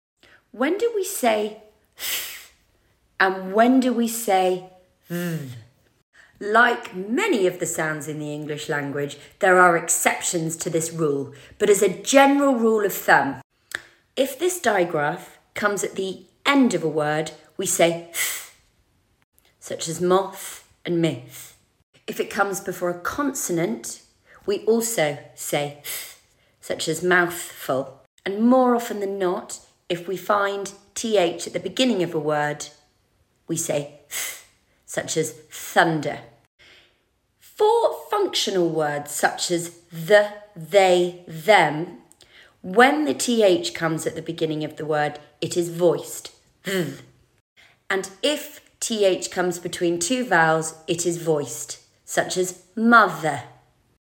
Watch this demo about when we say “th” softly (unvoiced) such as in moth… and when we say “th” in a harder way (voiced)… There are of course exceptions to this rule (because English is English and never straight forward!)